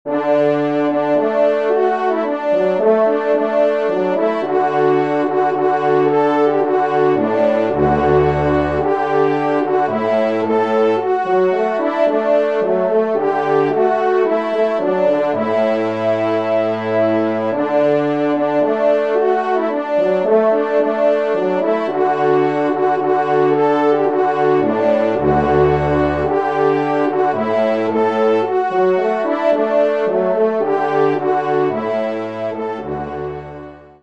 Genre : Divertissement pour Trompes ou Cors
Trompe 4 (Basse)  (en exergue)